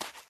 added base steps sounds
sand_2.ogg